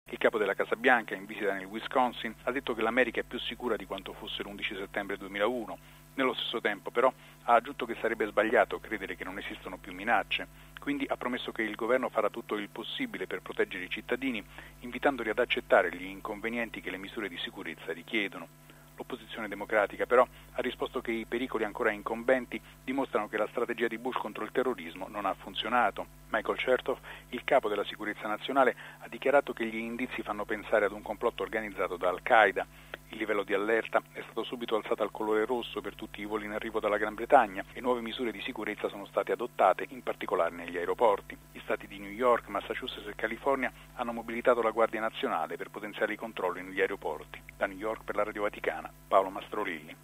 Il presidente americano George Bush ha commentato così gli arresti eseguiti in Gran Bretagna per gli attentati sventati. Innalzato comunque anche negli Stati Uniti l’allarme terrorismo. Da New York